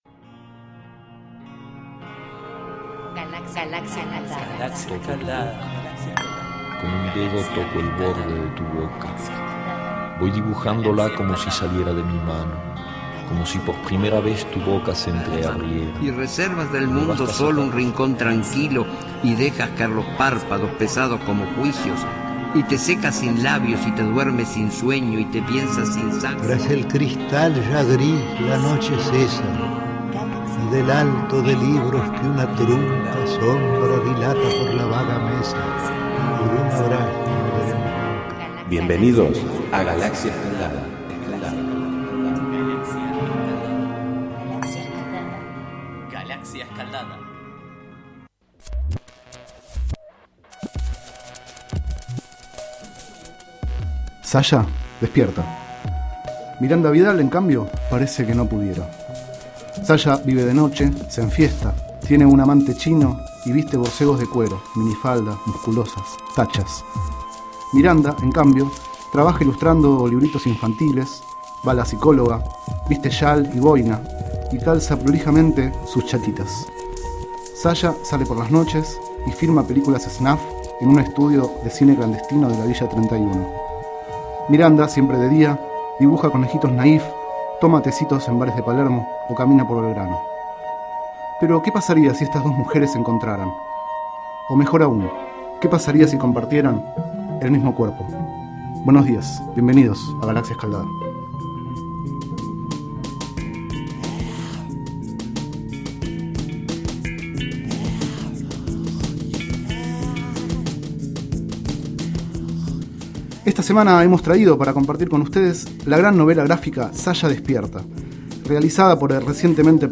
23º micro radial, realizado el 11 de agosto de 2012, sobre el libro Sasha despierta, de Carlos Trillo y Lucas Varela.